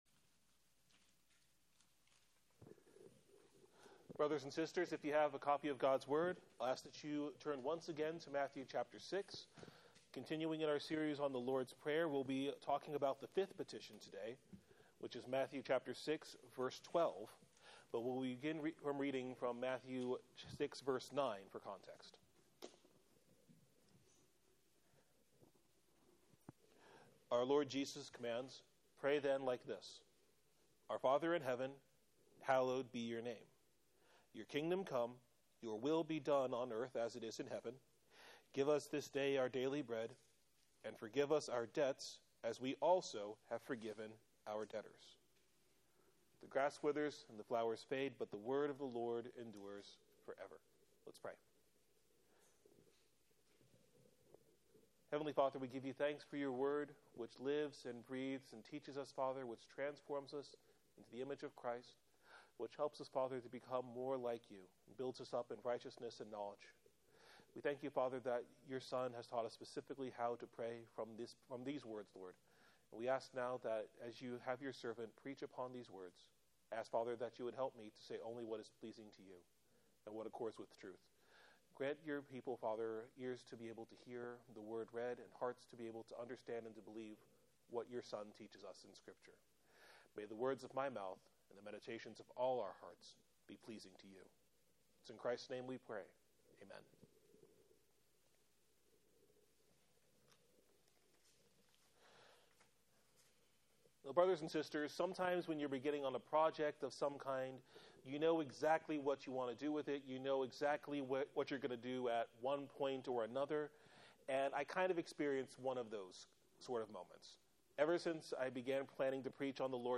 Sermon Text: Matthew 6:12 Theme: The fifth petition emboldens the sinner who forgives the debts of his neighbors to ask the Lord to pardon his own far greater debts because Christ Jesus paid for all of them on the cross.